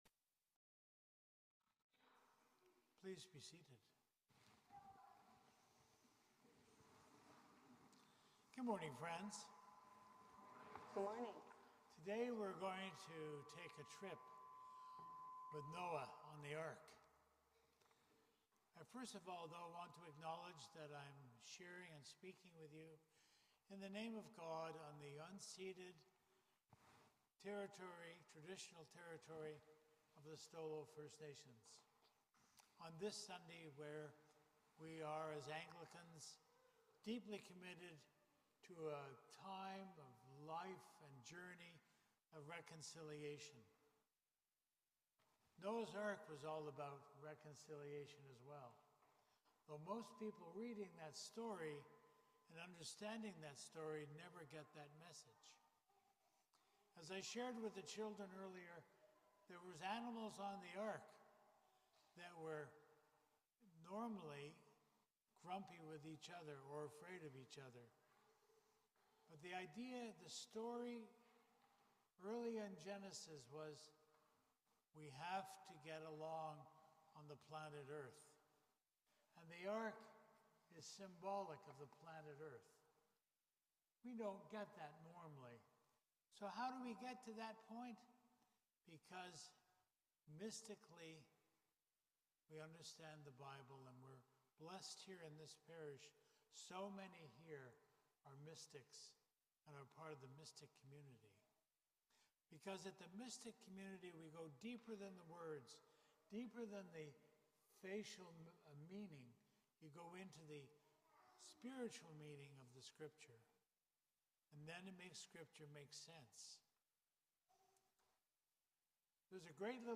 Sermon on Truth & Reconciliation Sunday / Fourth Sunday of Creationtide